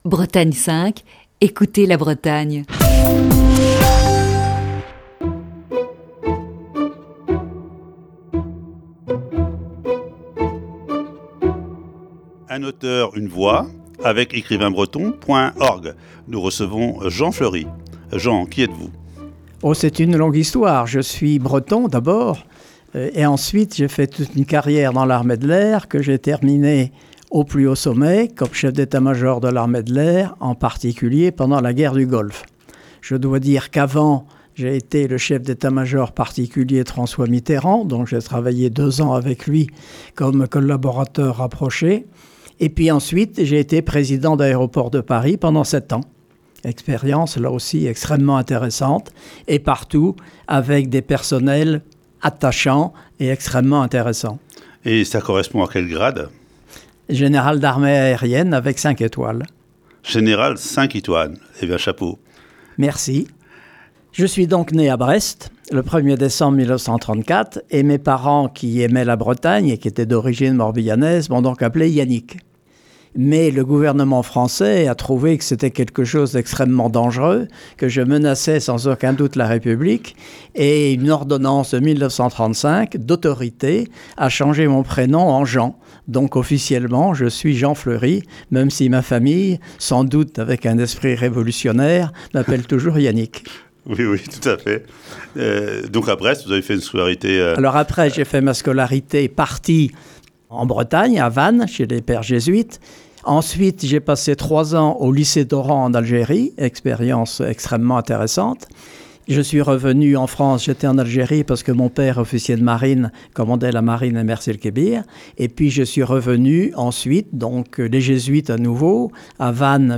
Émission du 28 décembre 2020.